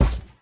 minecraft / sounds / mob / panda / step3.ogg